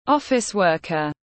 Nhân viên văn phòng tiếng anh gọi là office worker, phiên âm tiếng anh đọc là /ˈɒf.ɪs ˈwɜː.kər/.